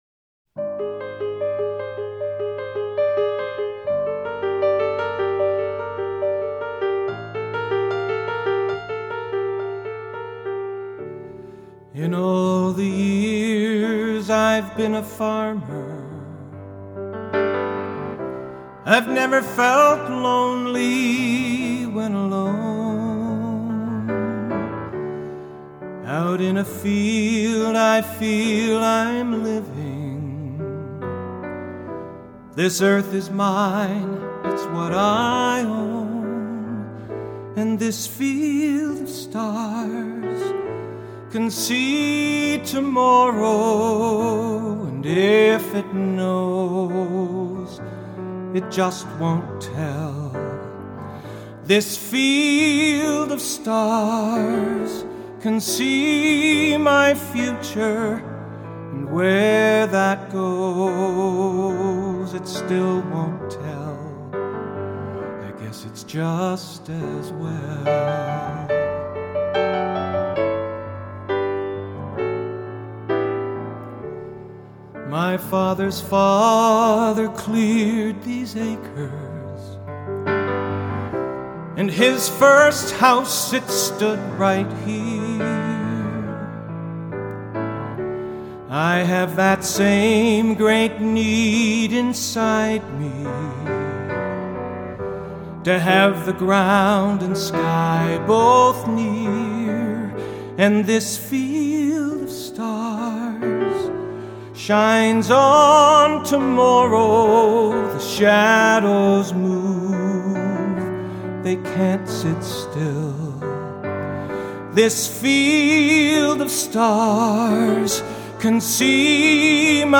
a CD of piano/vocal performances of each song,
Piano